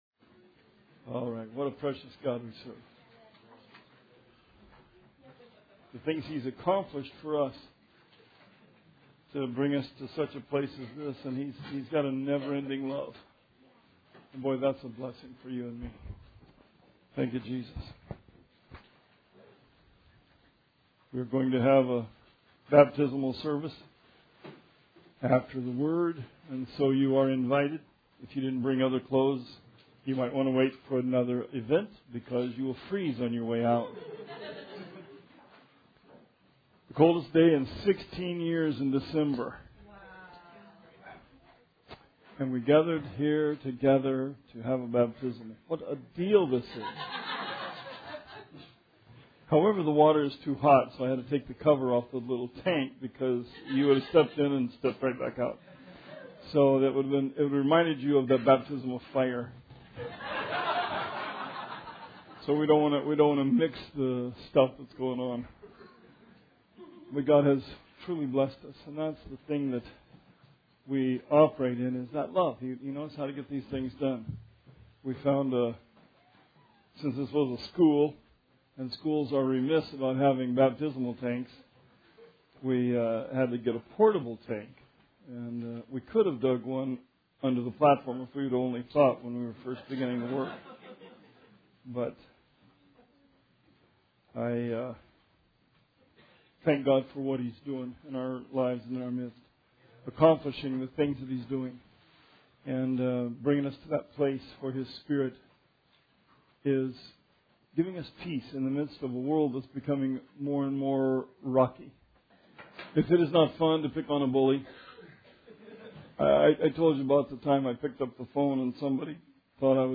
Sermon 12/18/16